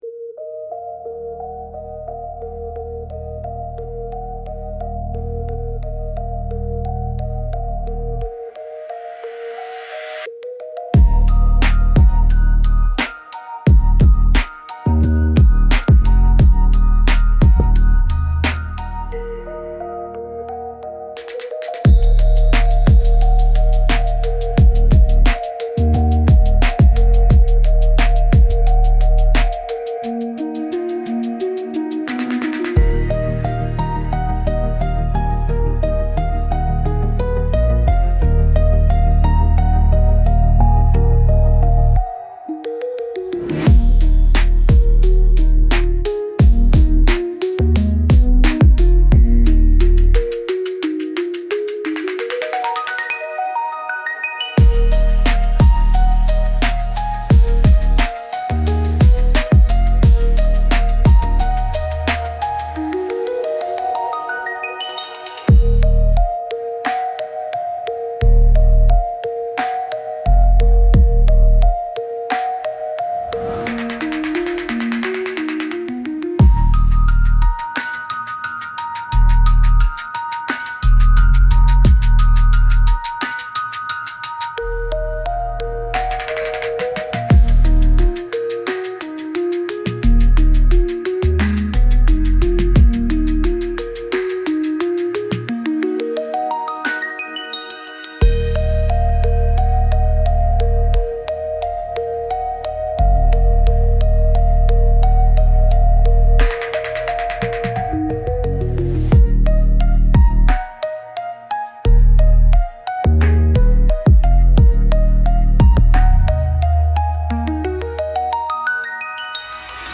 Instrumental - Real Liberty Media Dot XYZ- 2.47.mp3